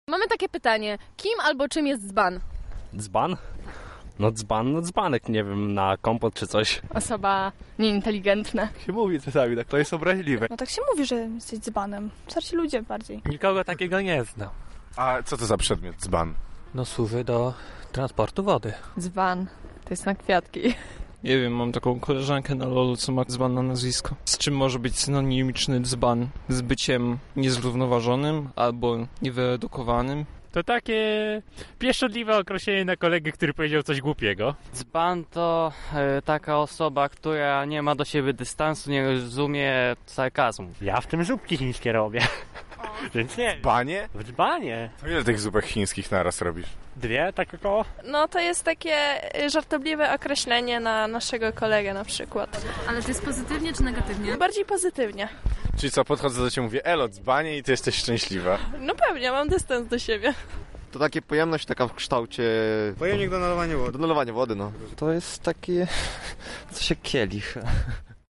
Nasi reporterzy zapytali również Lublinian co dla nich oznacza słowo dzban:
Sonda